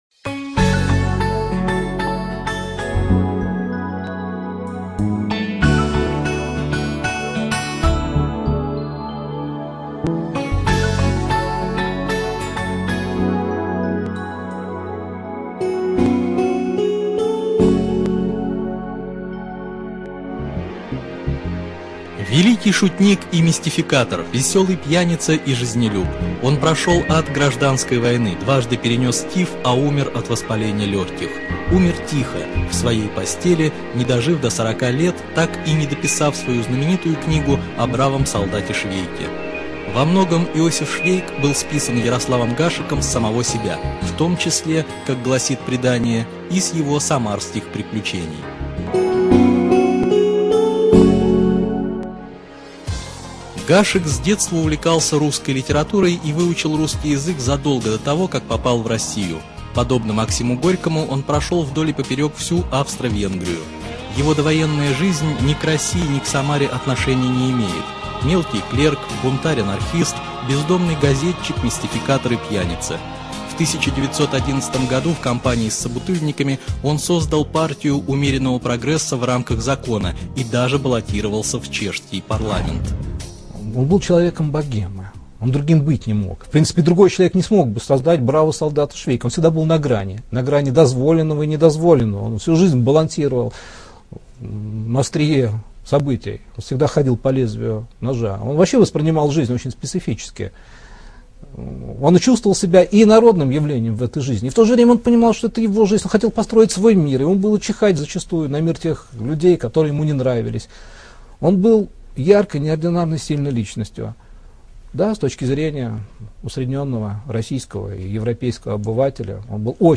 ЖанрБиографии и мемуары, Радиопрограммы